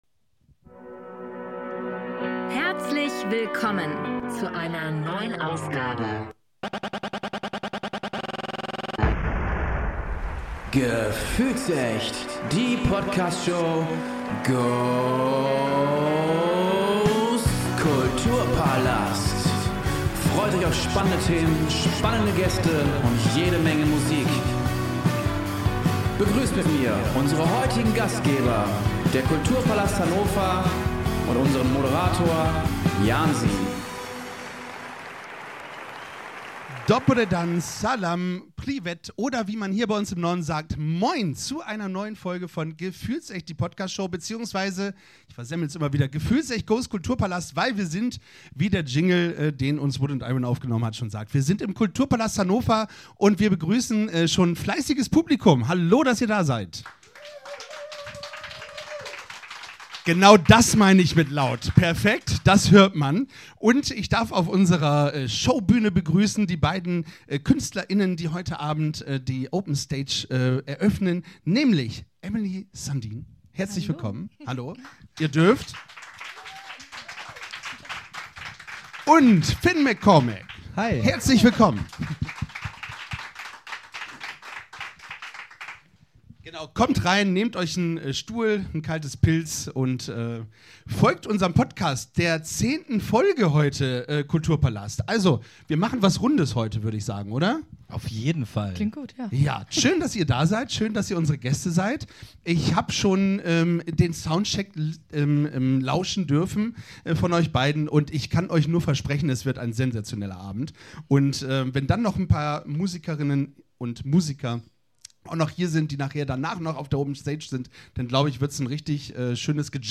Außerdem reflektieren sie, was ihr 10 Jahre jüngeres Ich wohl heute zu ihnen sagen würde und geben persönliche Einblicke in ihre künstlerische Entwicklung. Das Publikum kommt ebenfalls zu Wort und sorgt für eine interaktive und unterhaltsame Atmosphäre.